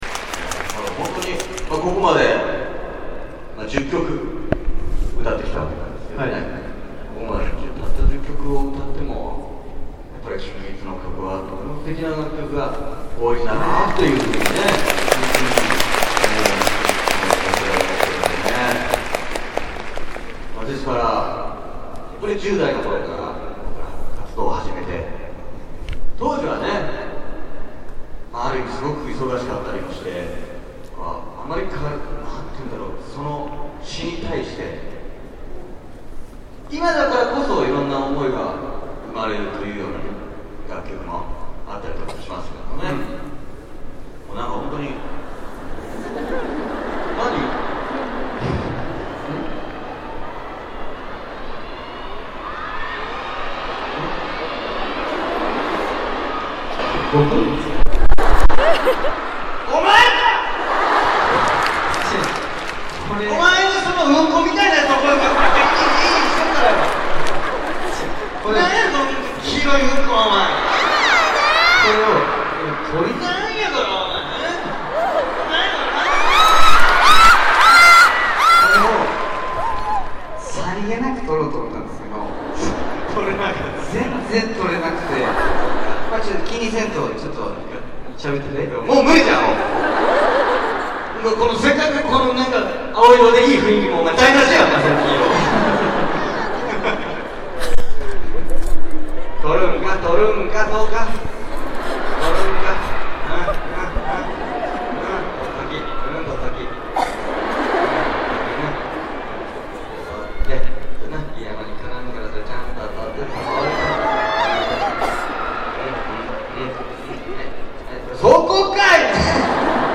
※ 아, 여기 중간중간 상당히 하이톤의 여자팬분 목소리가 나오는데.... 결코 저 아닙니다ㅠ
이분만 텐션 장난 아니셔서 킨키가 하는 말 하나하나 행동 하나하나에 엄청난 리액션이ㅋㅋㅋ
녹음한거 돌려듣다가 내가 이언니 콘서트 왔는 줄 알고 착각했음ㅋㅋㅋㅋㅋ